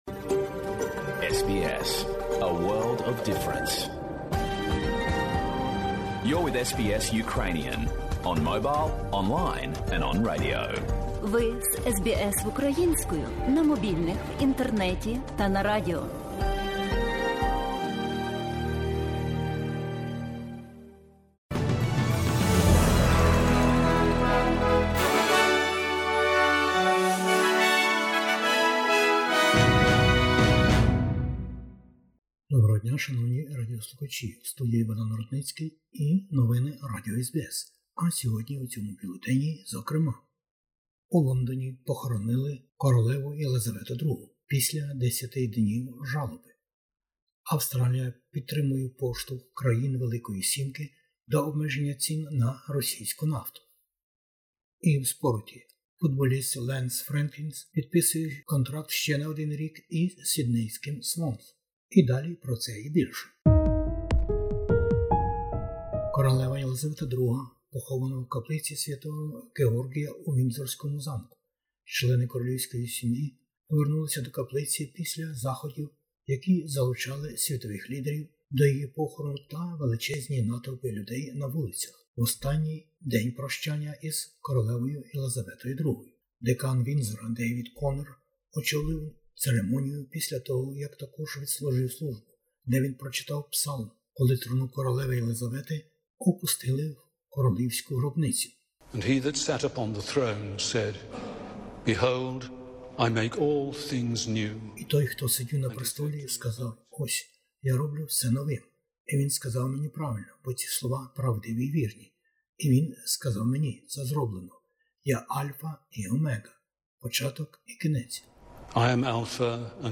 Бюлетень SBS новин - 20/09/2022